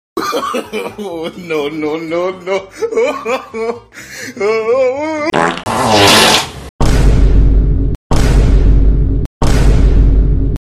Shart: Instant Play Sound Effect Button